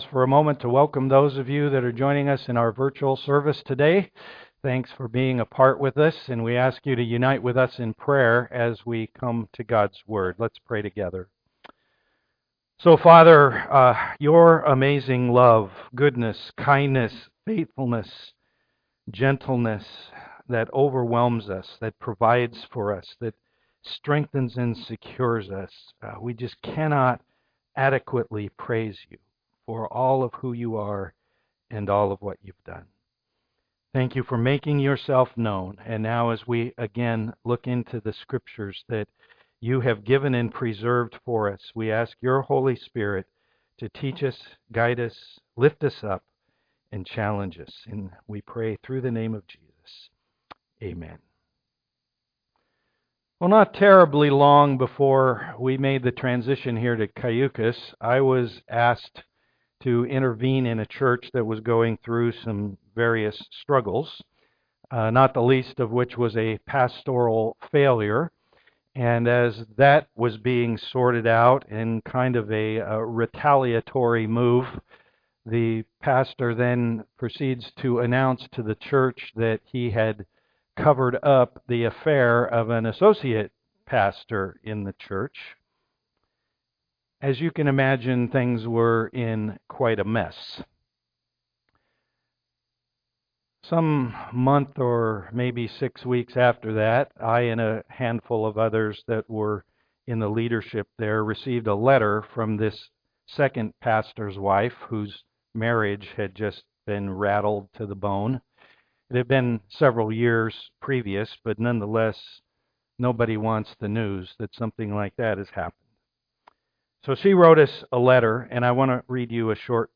Romans 8:31-39 Service Type: am worship Everyone wants to be loved!